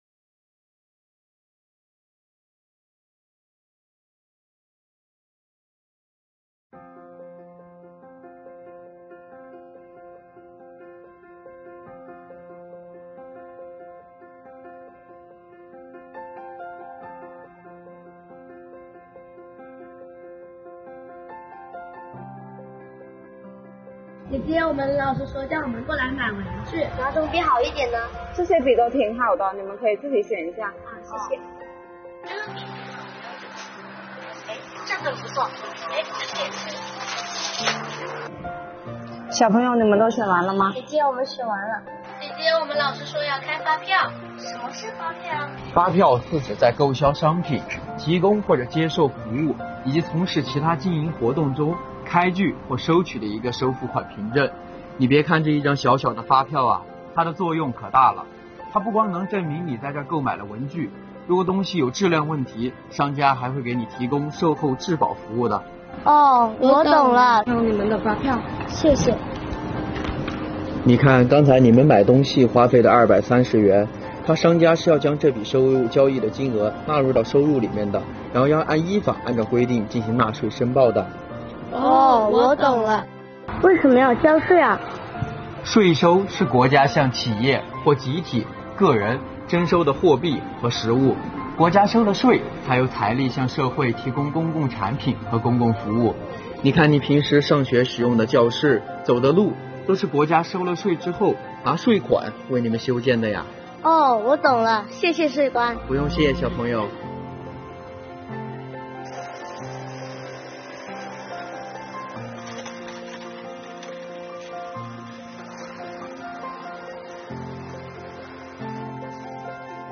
钦州市税务局通过“发票与税收的关系”“什么是偷税漏税”“个人所得税就在您身边”三个情景小剧场讲述我们常常能遇到的涉税业务，以此来说明税收的应用之广泛。